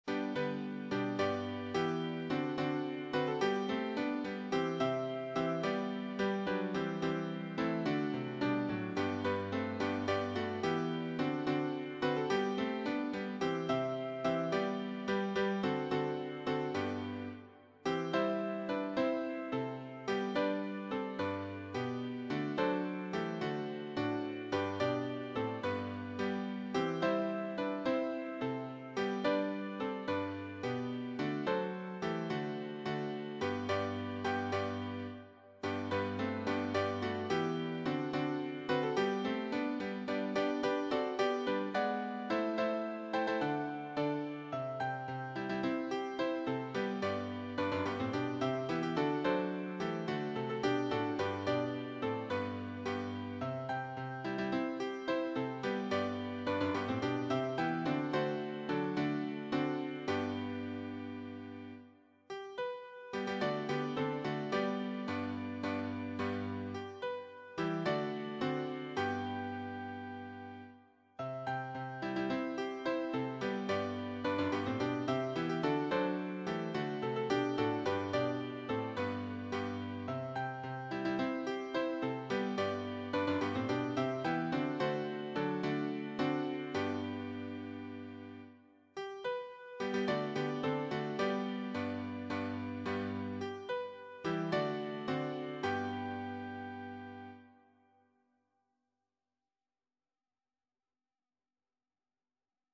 choir SATB